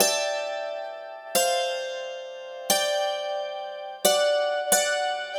Hammered Ducimer 05.wav